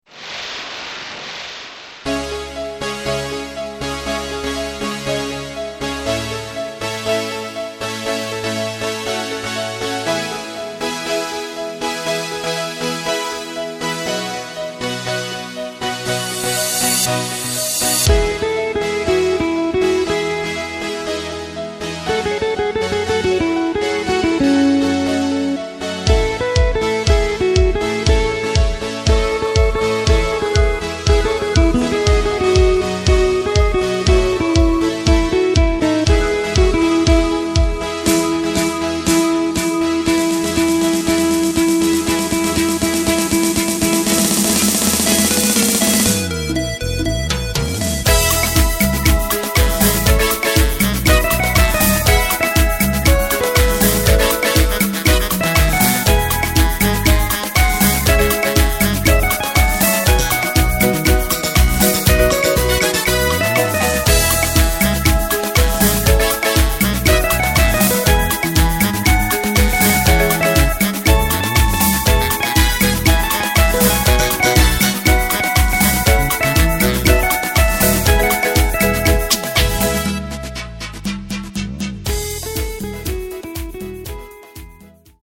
Takt:          4/4
Tempo:         120.00
Tonart:            E
Spanischer PopSong aus dem Jahr 2014!